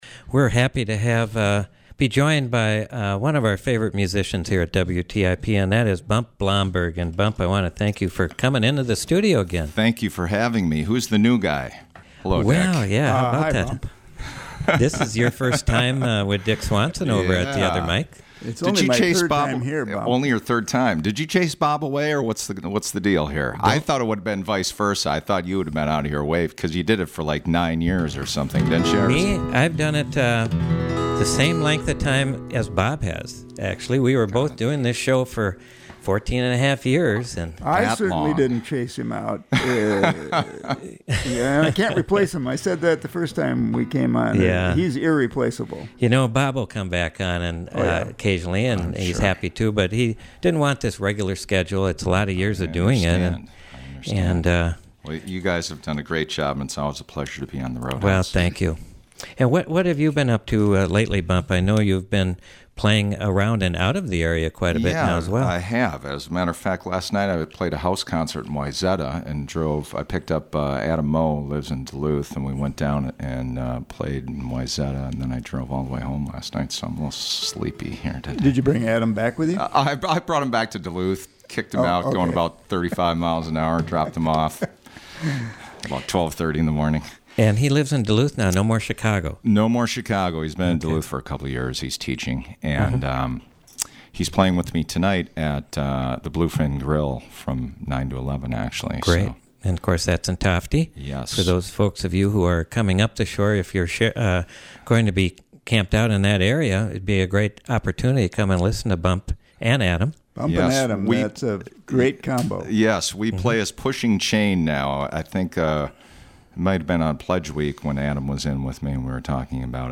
Singer-songwriter
stopped in to Studio A June 28
play gorgeous original music